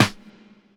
Medicated Snare 23.wav